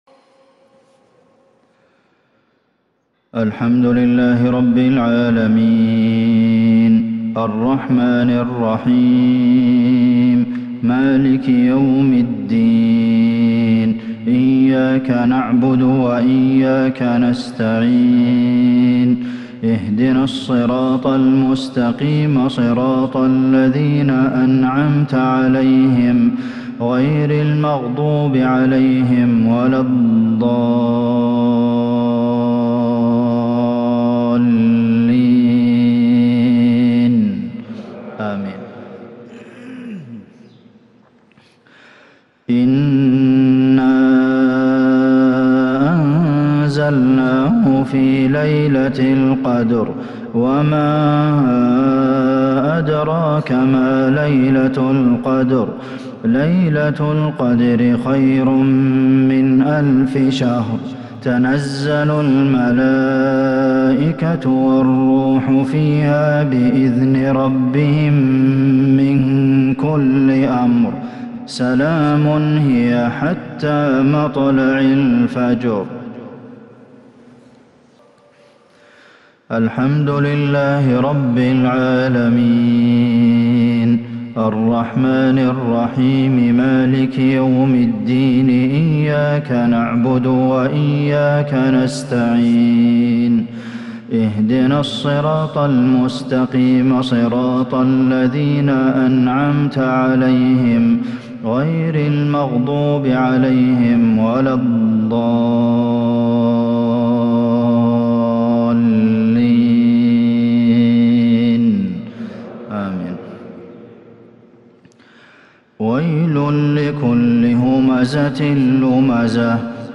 مغرب الأحد 1-5-1443هـ سورتي القدر والهمزة | Maghrib prayer Surah Al-Qadr and Al-Humazah 5/12/2021 > 1443 🕌 > الفروض - تلاوات الحرمين